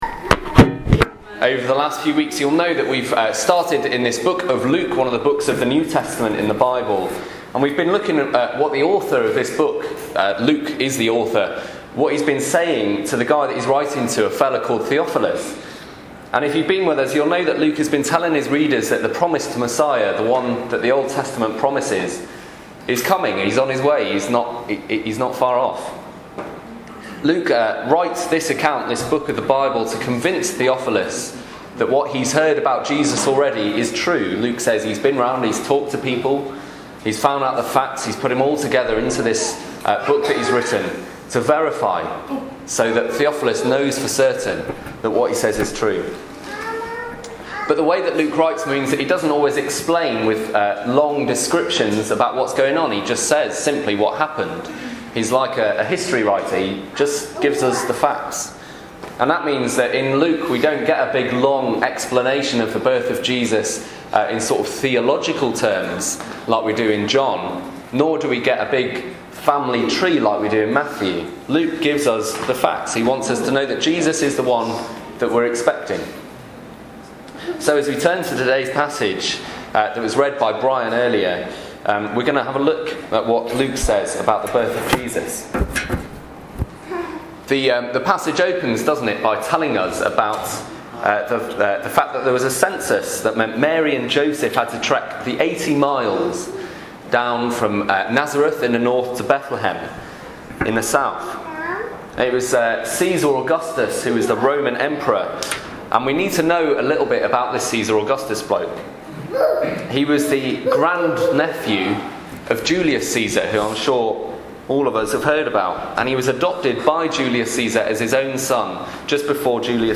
talk from Christmas Day